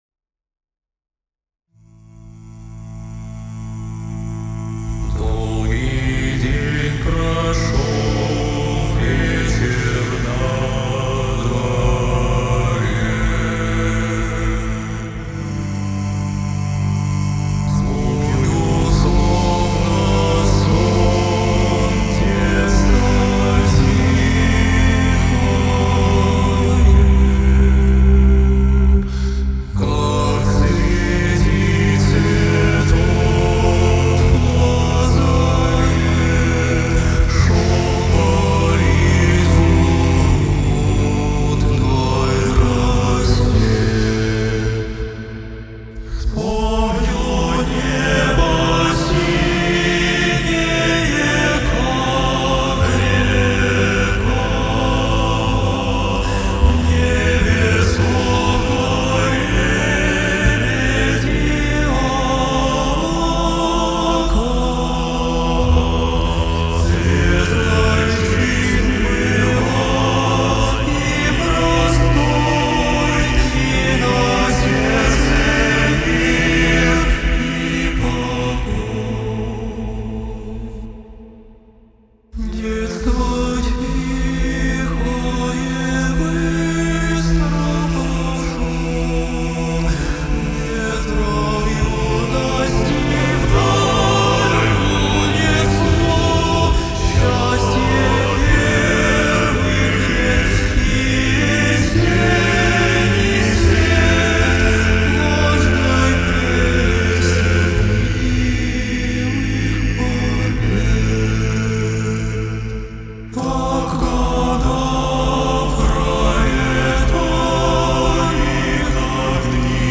Платный мастеринг вокального ансамбля